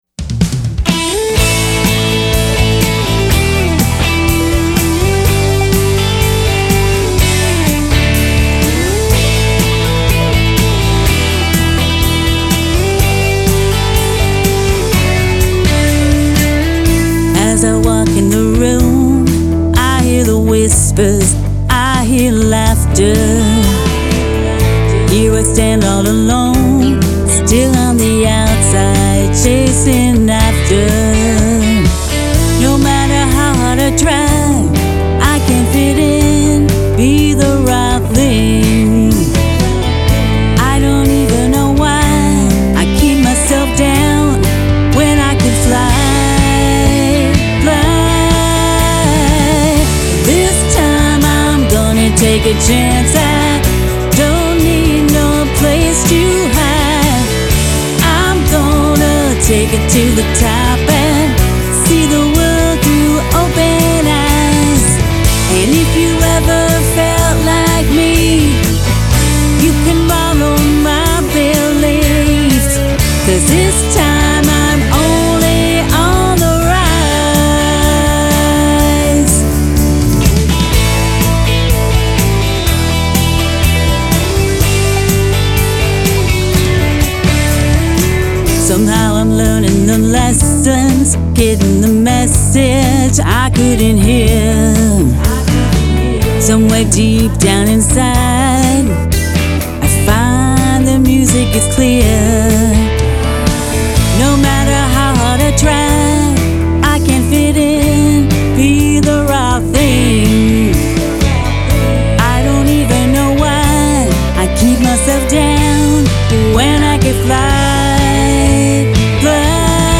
Pop/Rock/Country